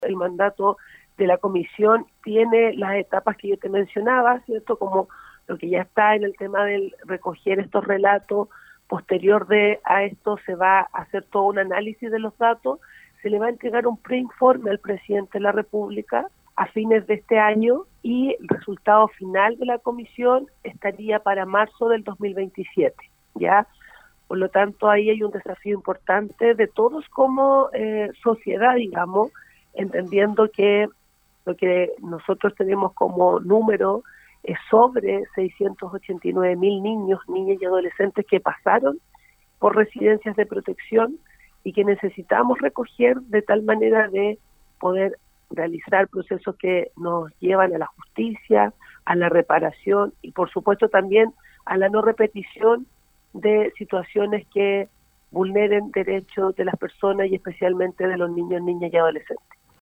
La seremi de Gobierno, Jacqueline Cárdenas, indicó que “si bien la Comisión fue creada por el Presidente Gabriel Boric, lo importante es dejar muy claro que esto tiene que ser una tarea de Estado”.